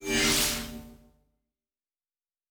Sci-Fi Sounds / Doors and Portals / Teleport 3_1.wav
Teleport 3_1.wav